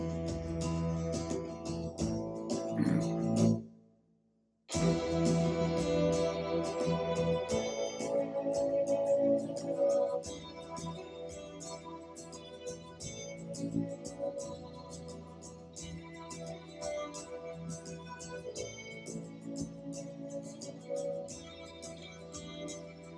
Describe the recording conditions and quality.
Audacity recorded audio, distorting, and low volume on playback Version 3.6.1 this distortion and low volume problem has occurred in previous versions and I have no idea why it occurs.